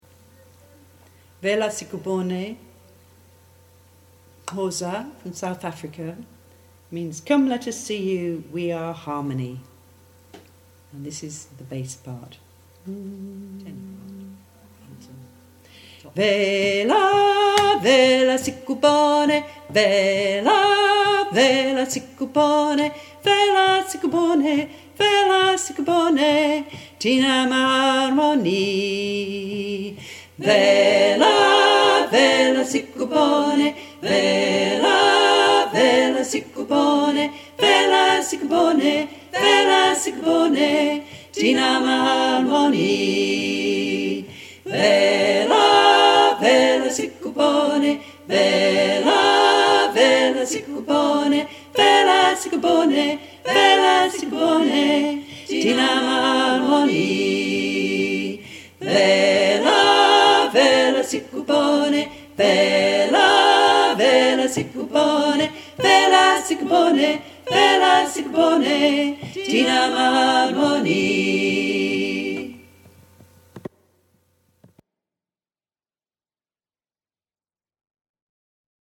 Guidance recording of 'Vela Vela' for Cycle of Songs Choir